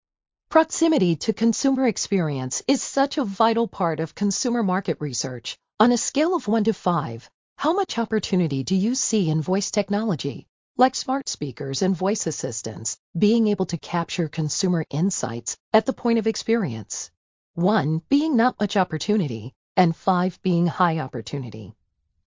- Voice Ambassadors™ recording the messaging
The entire process from idea to launch took about 4 days including the custom recordings by a professional Voice Ambassador™ and the approval process.
Road_to_samplecon_question_2.mp3